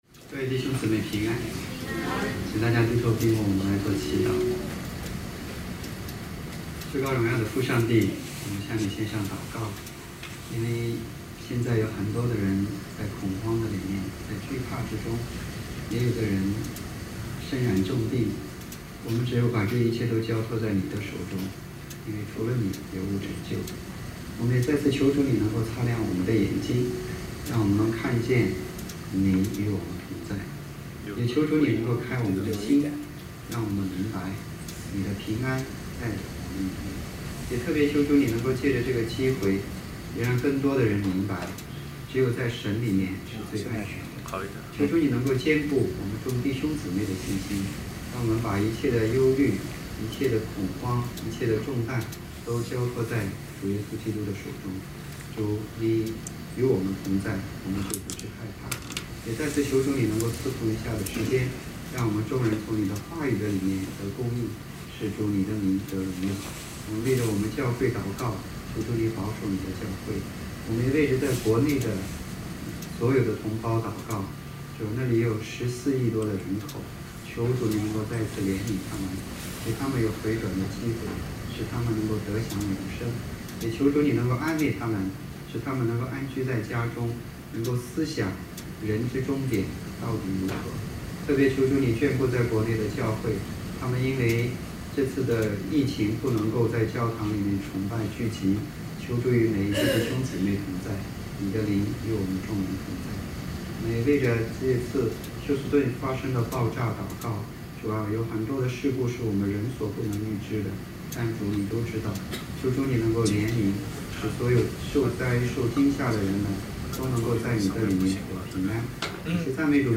国语讲道2020